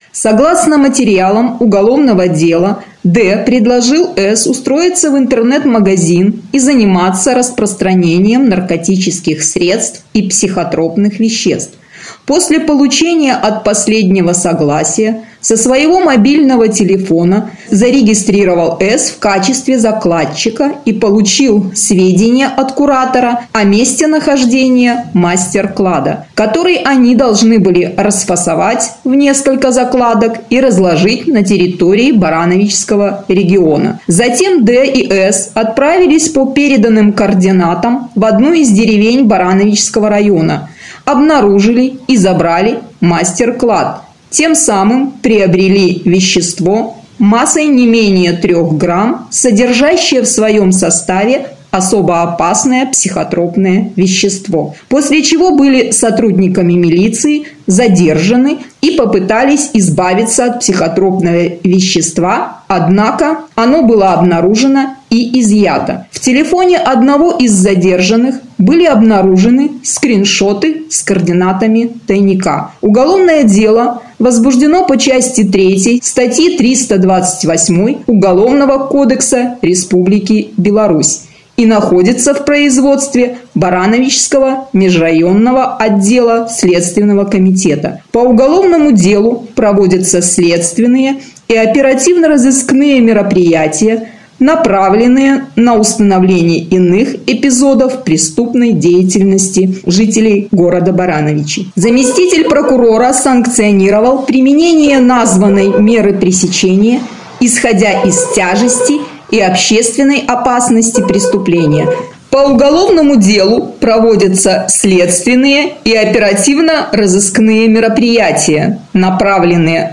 Молодые люди незаконно с целью сбыта приобрели и хранили особо опасное психотропное вещество, — рассказала заместитель Барановичского межрайонного прокурора Инна Данильчик.